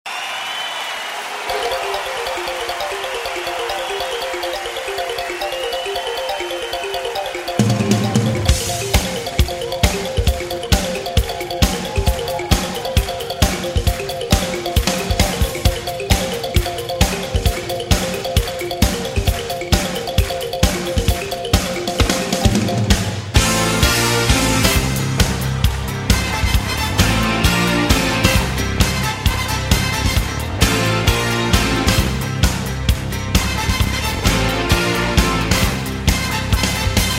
- Chanson à l'entrée des joueurs sur le stade :